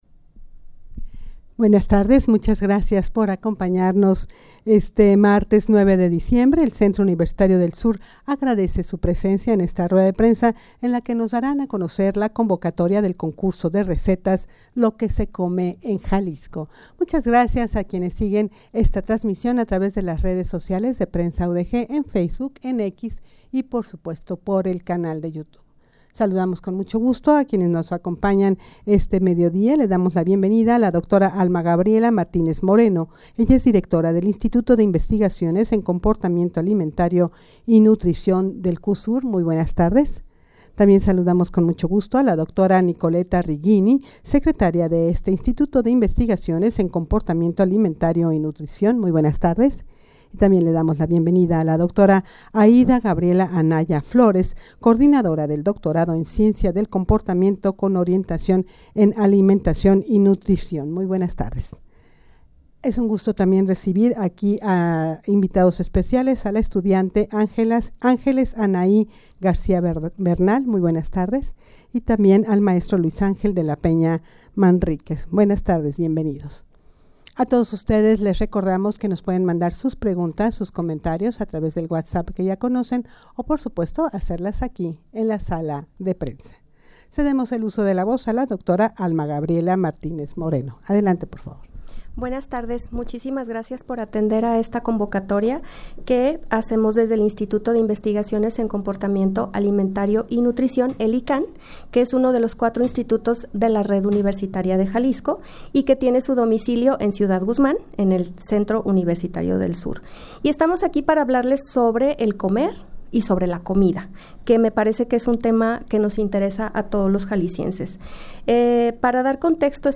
Audio de la Rueda de Prensa
rueda-de-prensa-para-dar-a-conocer-la-convocatoria-del-concurso-de-recetas-lo-que-se-come-en-jalisco.mp3